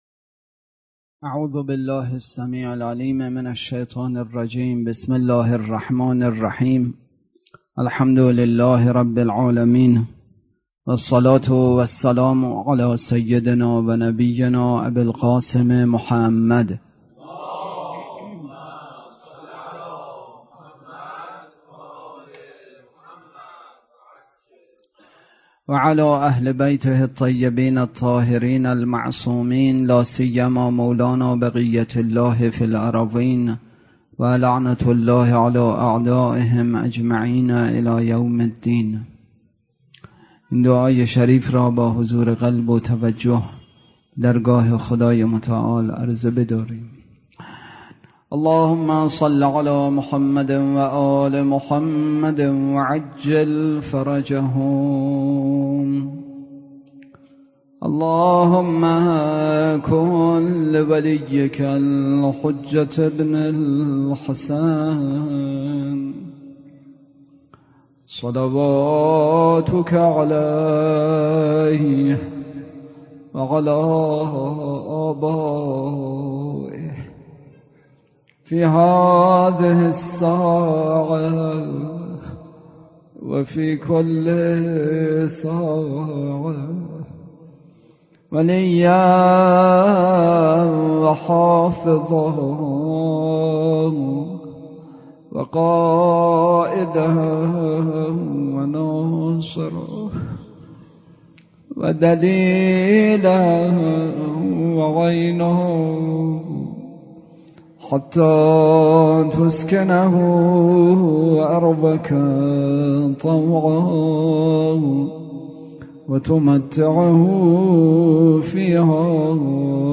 برگزارکننده: هیئت مصباح الهدی
احکام: احکام شئ گم شده بحث اصلی: تذکراتی پیرامون ماه رجب، روایاتی از زندگی امام هادی(ع) روضه: ورود اسرا به مجلس یزید